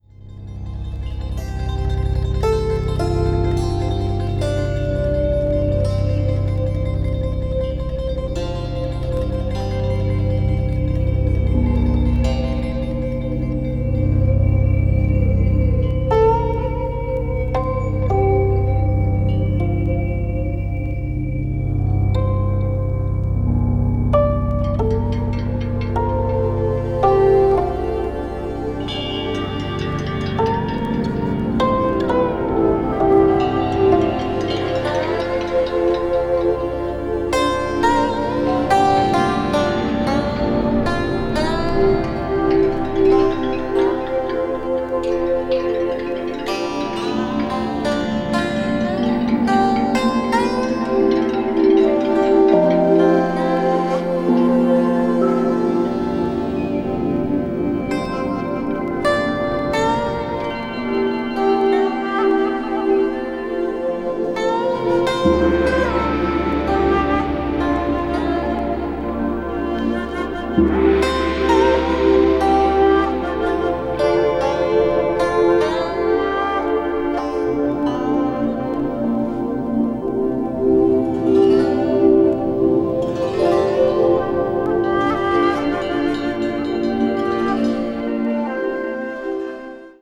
Chinesische Meditationsmusik
とても美しいサウンドです。
ambient   china   meditation   new age   world music